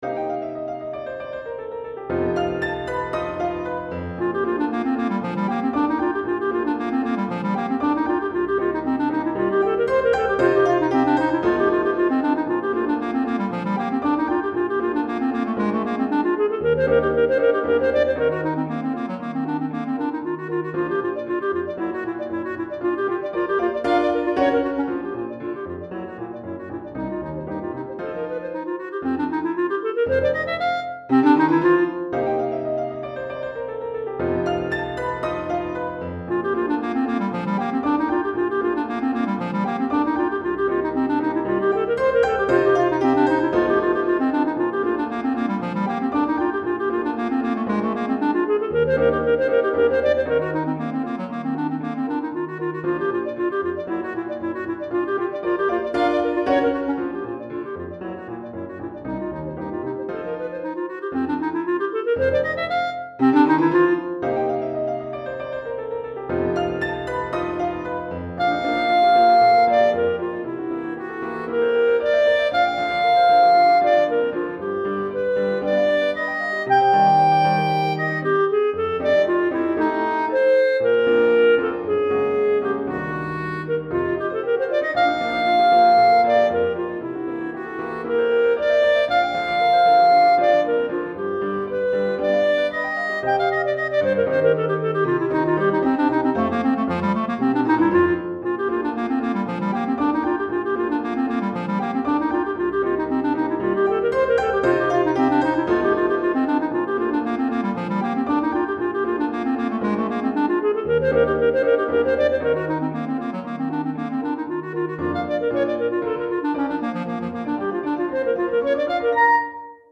Clarinette en Sib et Piano